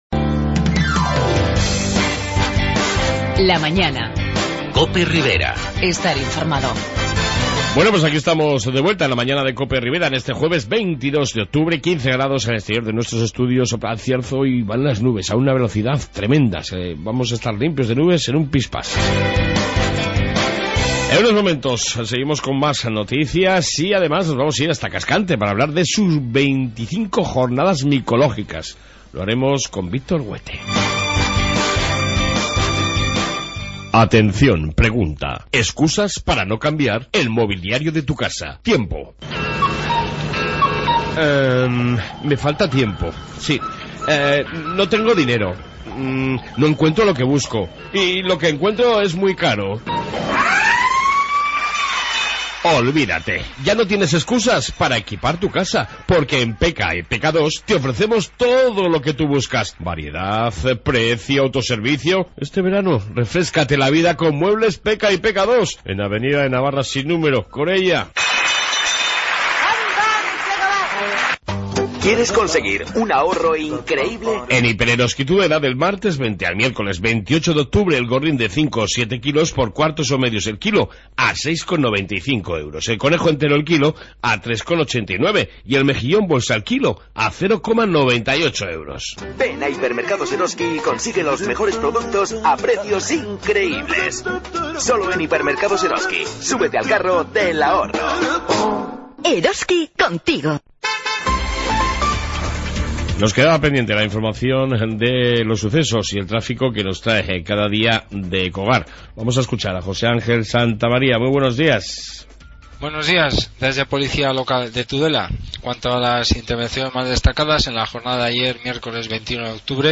En esta 2 parte Información Policía Municipal, Noticias riberas y entrevista